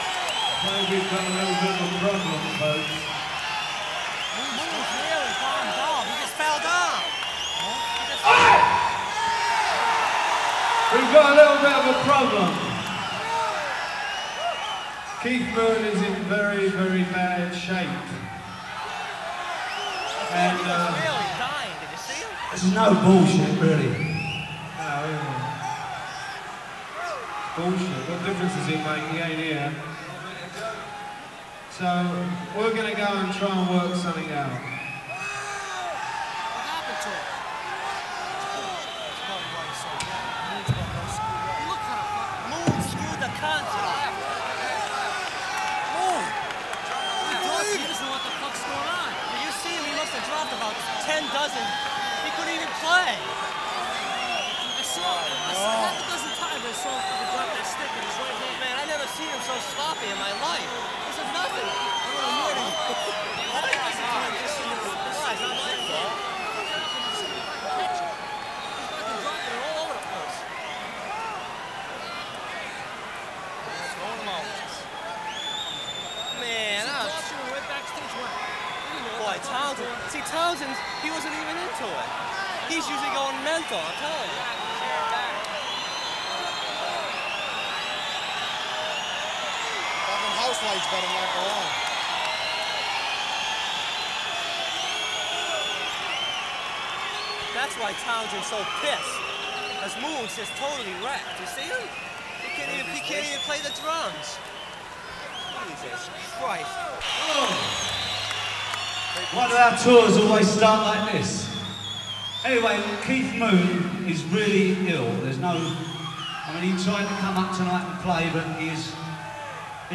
Format/Rating/Source: CD - B- - Audience
Comments: Decent audience recording.